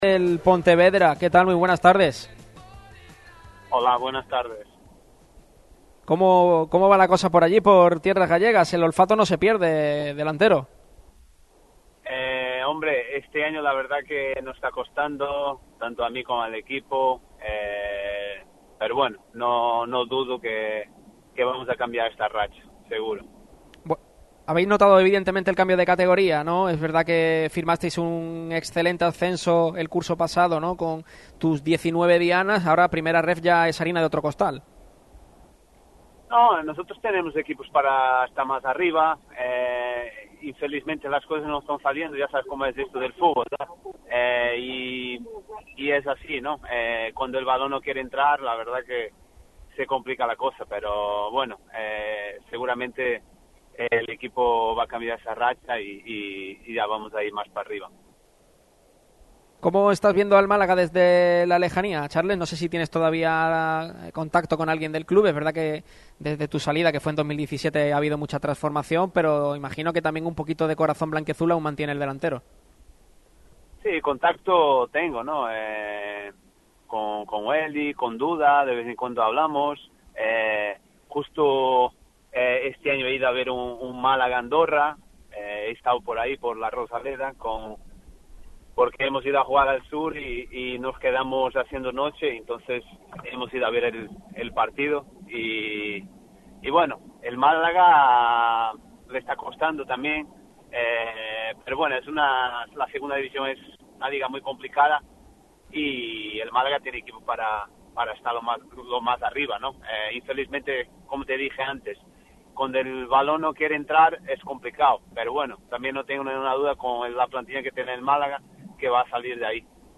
El ex delantero del Málaga CF, Charles Dias estuvo hoy en una entrevista para Radio Marca Málaga.